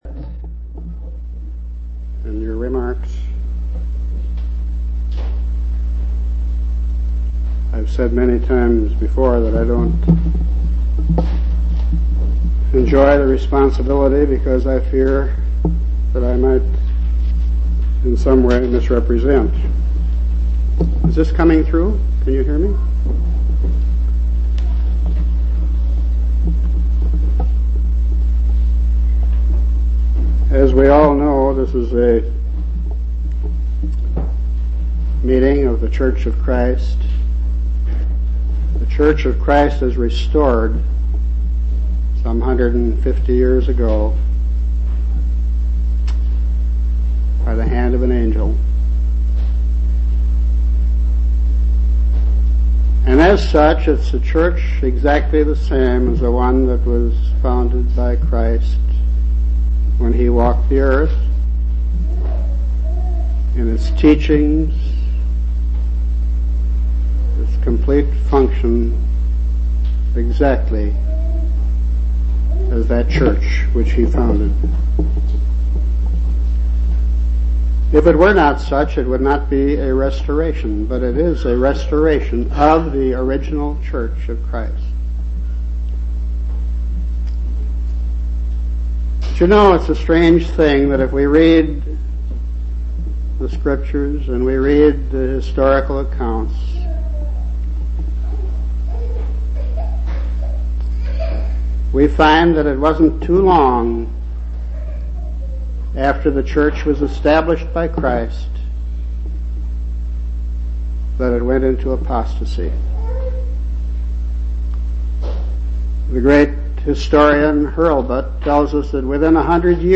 11/9/1980 Location: Temple Lot Local Event